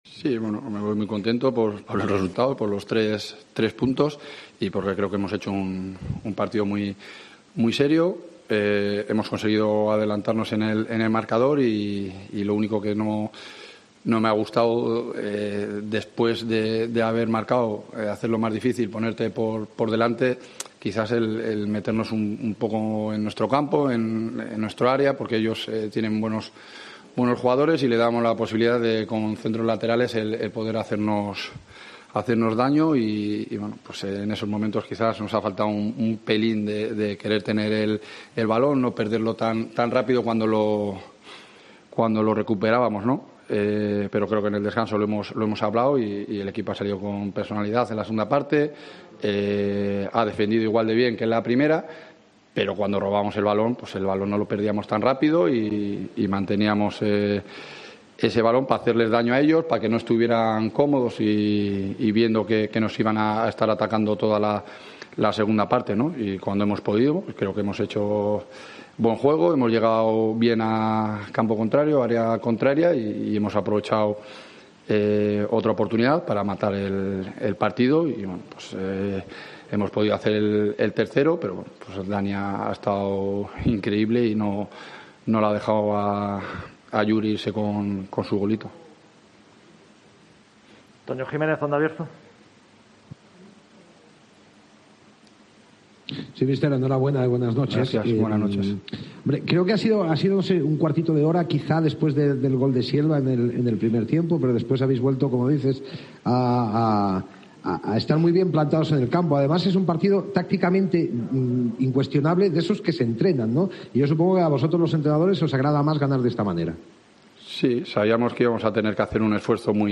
Postpartido